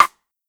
ClpRim15.wav